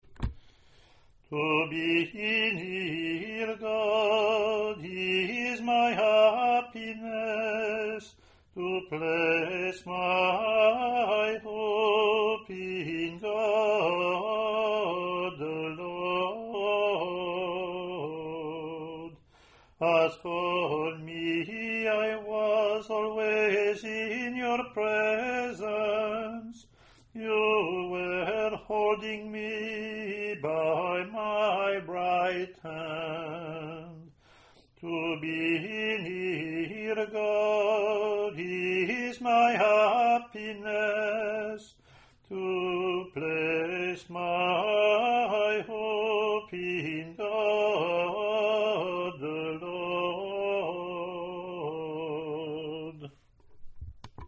English antiphon – English verse